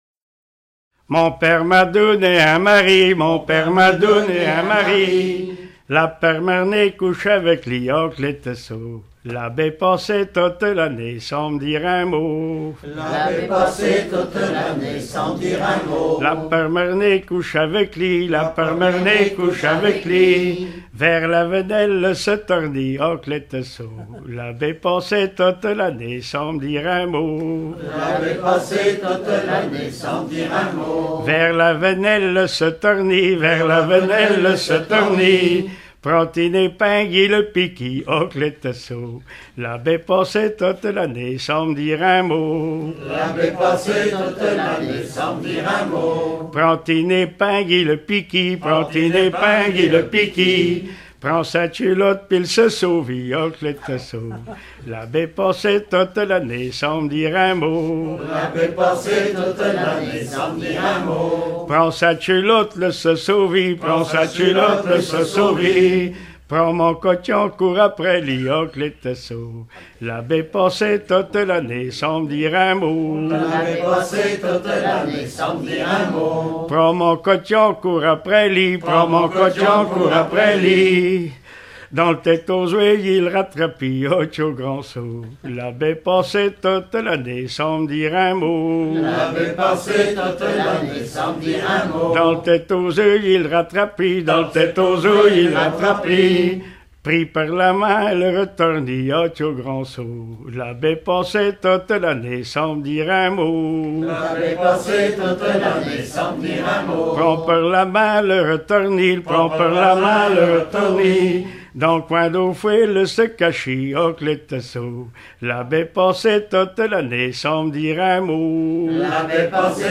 Langue Patois local
Genre laisse